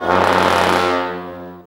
TIBET BRS  R.wav